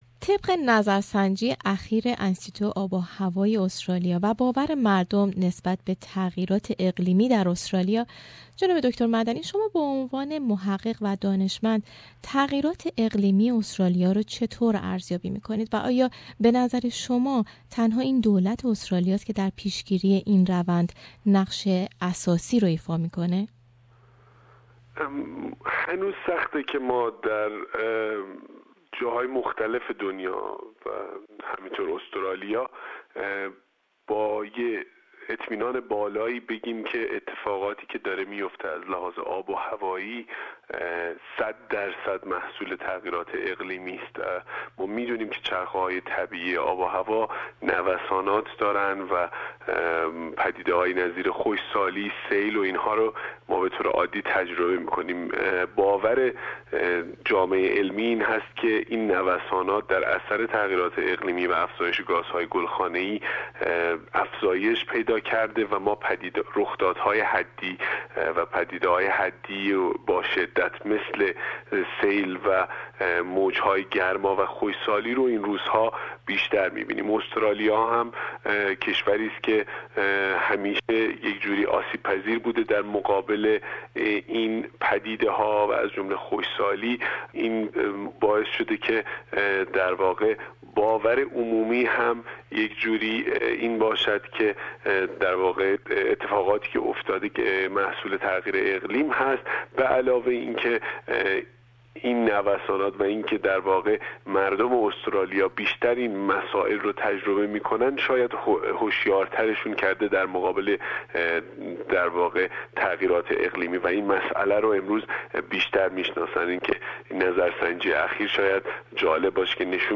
در این راستا به مصاحبه ای که با آقای دکتر کاوه مدنی عضو اتحادیه علوم زمین اروپا و استاد مدیریت آب و محیط زیست امپریال کالج لندن و یکی از دانشمندان برجسته در علوم زمین ترتیب دادیم که میتوانید دانلود و گوش نمایید.